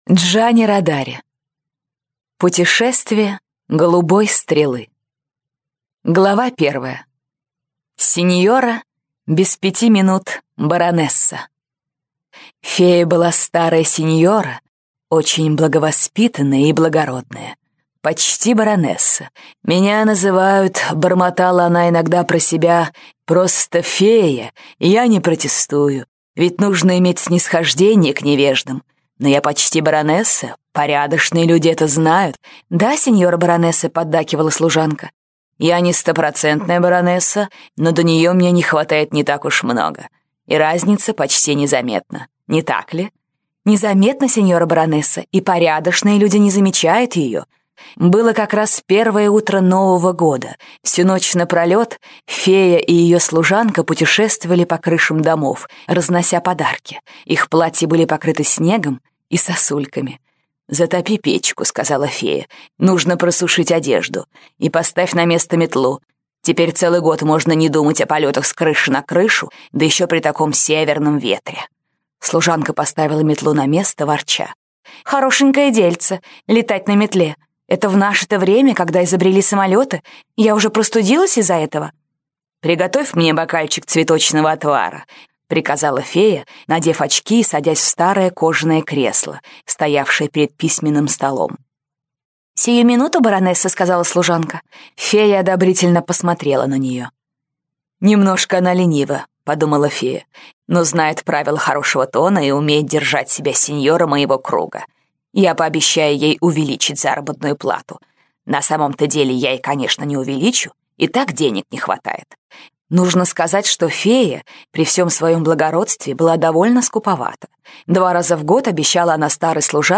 Путешествие голубой стрелы - аудиосказка Родари - слушать